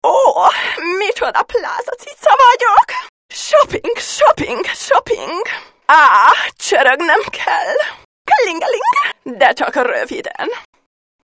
. SMS hangok .